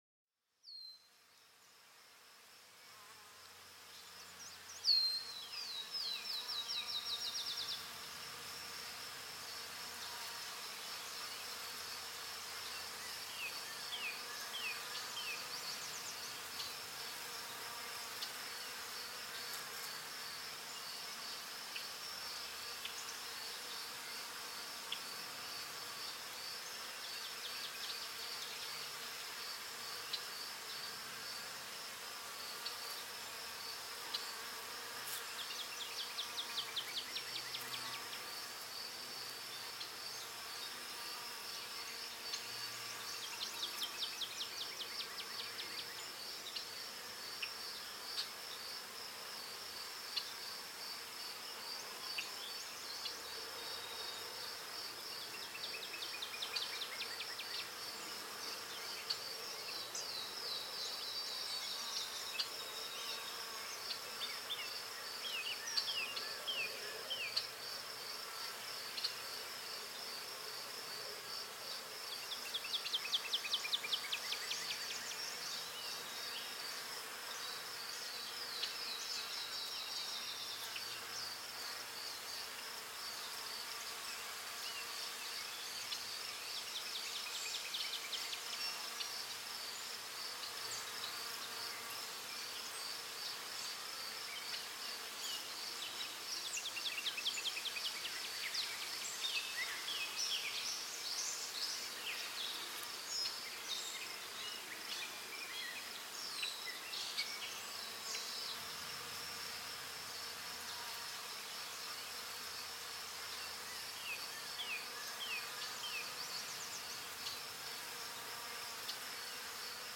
Canto de Aves en el Bosque para una Relajación Óptima